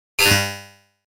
جلوه های صوتی
دانلود صدای ربات 18 از ساعد نیوز با لینک مستقیم و کیفیت بالا